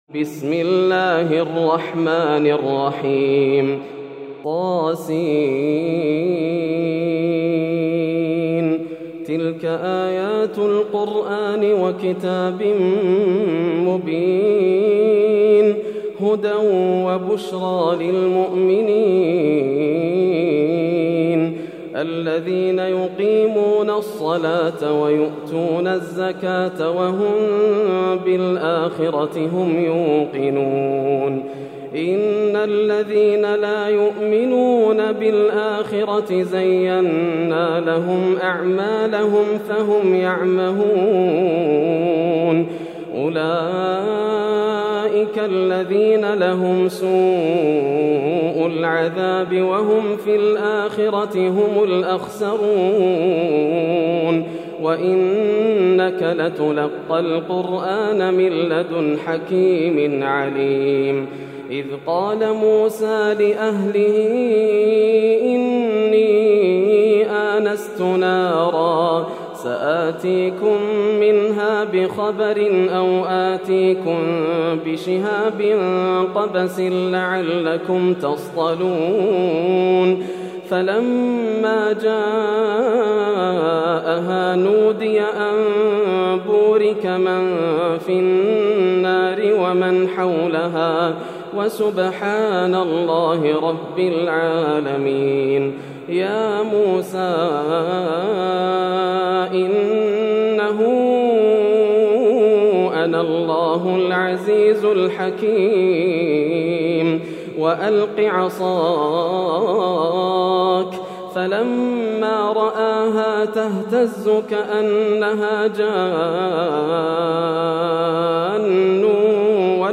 سورة النمل > السور المكتملة > رمضان 1431هـ > التراويح - تلاوات ياسر الدوسري